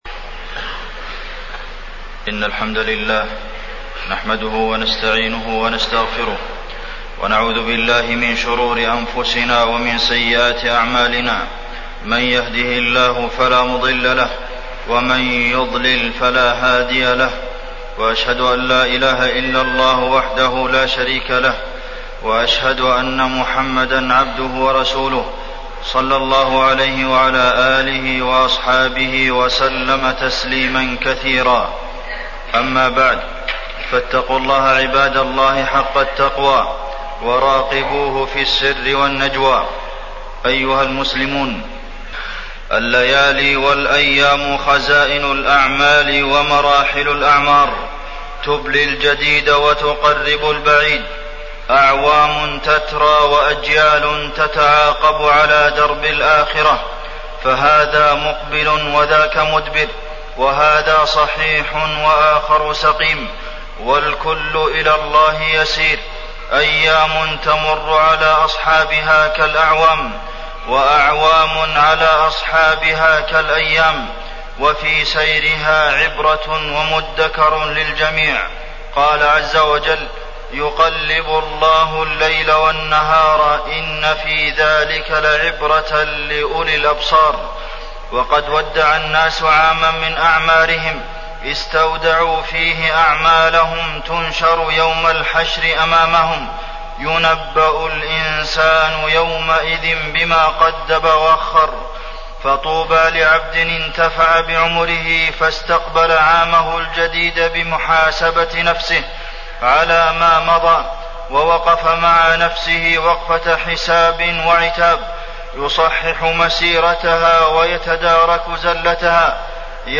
تاريخ النشر ٢٨ ذو الحجة ١٤٢٩ هـ المكان: المسجد النبوي الشيخ: فضيلة الشيخ د. عبدالمحسن بن محمد القاسم فضيلة الشيخ د. عبدالمحسن بن محمد القاسم تفقد عيوب النفس